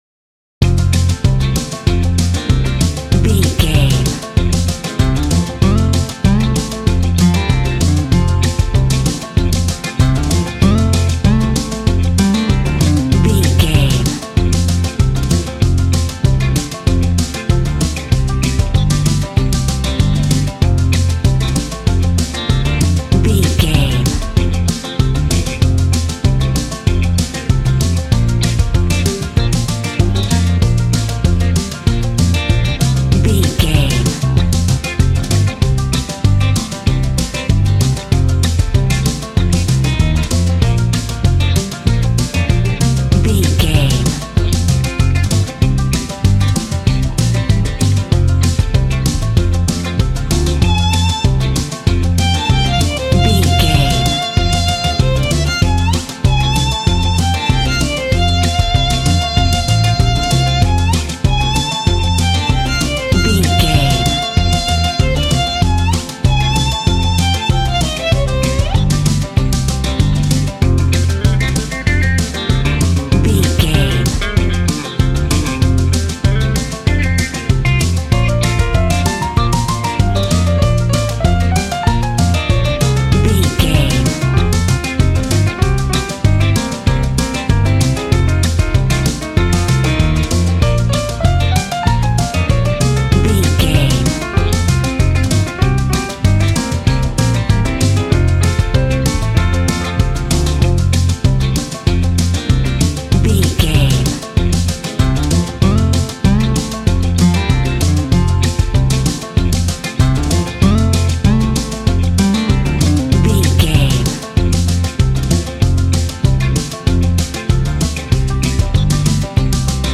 Ionian/Major
Fast
fun
bouncy
positive
double bass
drums
acoustic guitar